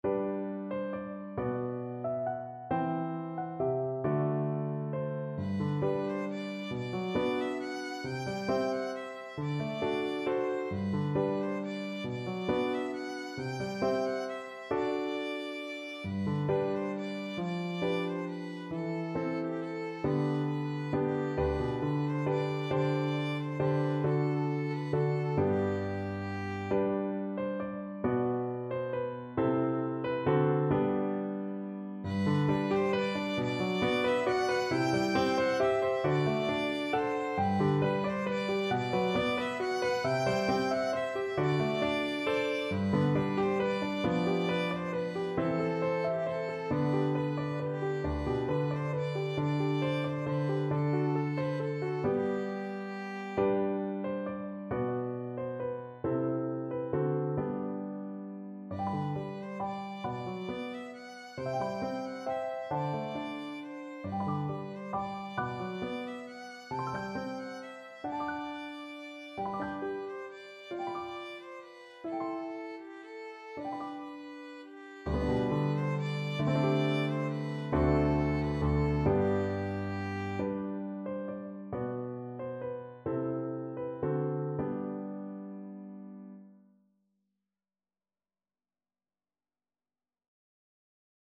.=45 Gently Lilting .=c.45
6/8 (View more 6/8 Music)